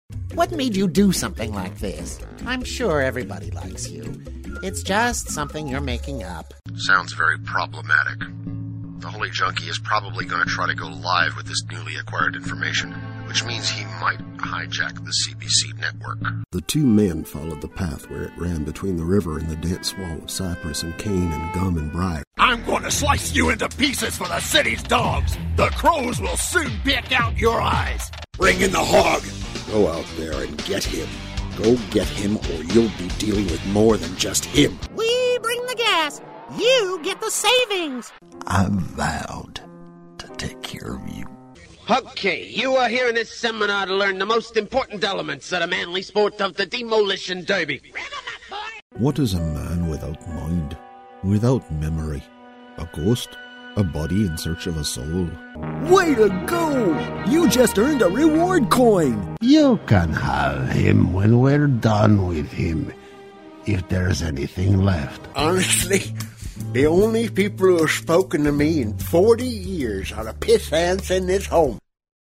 Voice of reason, warm, real, clear friendly, articulate, clear, versatile, and confident. Paternal, warm, conversational, versatile, and confident... and professional. Home studio & phone patch for direction via Skype.
Sprechprobe: Industrie (Muttersprache):